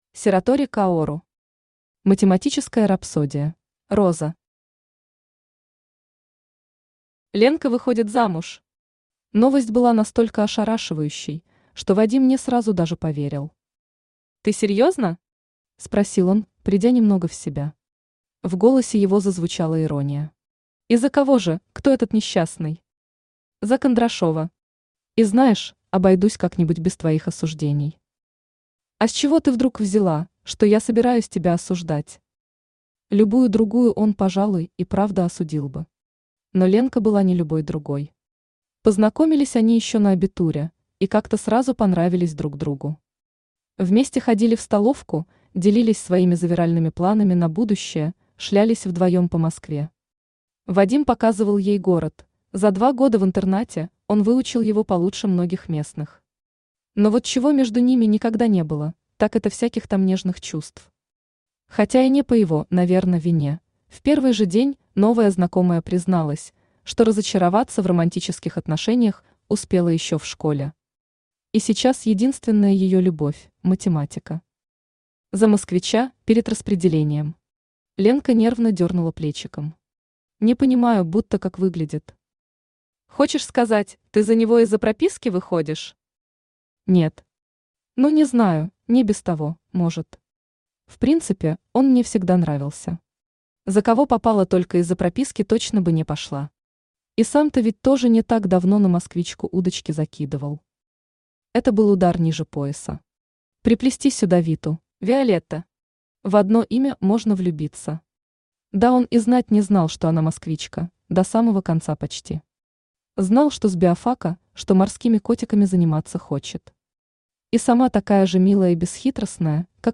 Аудиокнига Математическая рапсодия | Библиотека аудиокниг
Aудиокнига Математическая рапсодия Автор Сиратори Каору Читает аудиокнигу Авточтец ЛитРес.